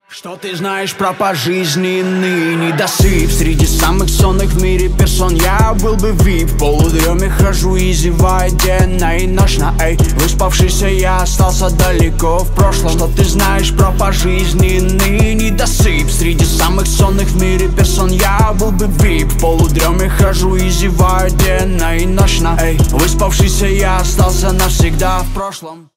Юмор